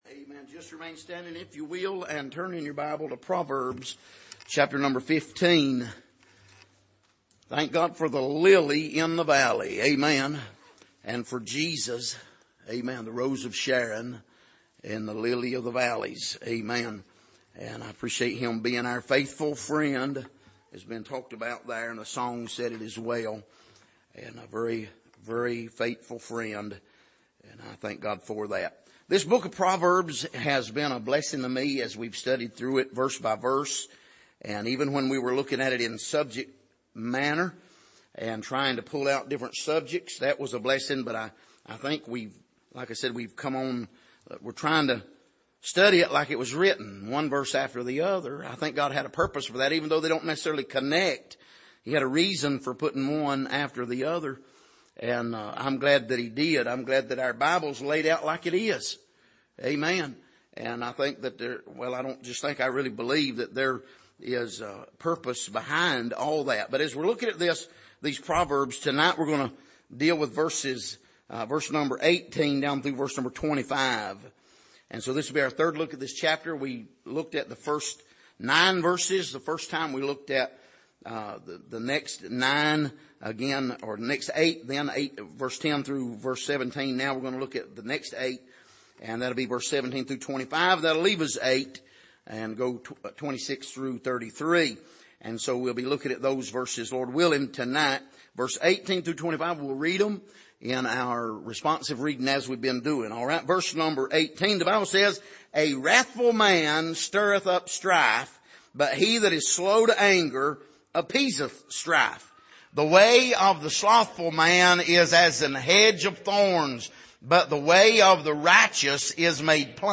Passage: Romans 5:1-11 Service: Sunday Morning